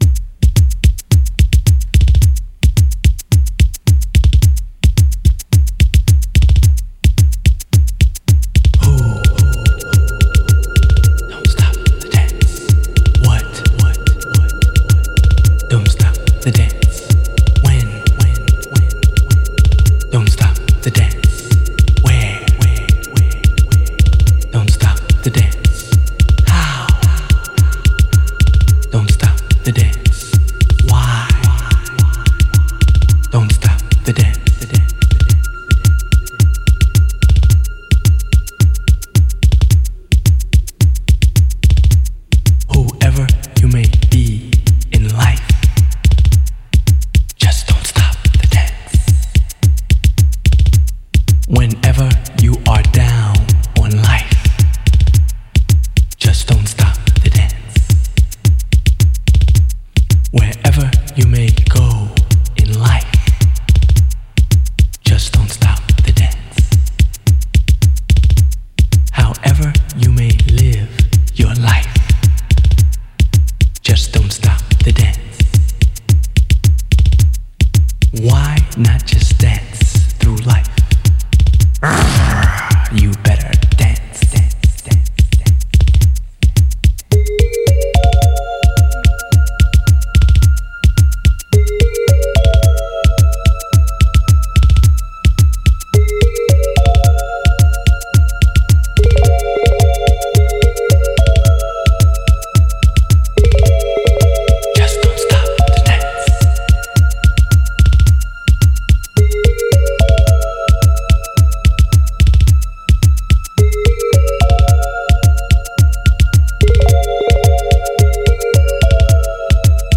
early NY underground house tracks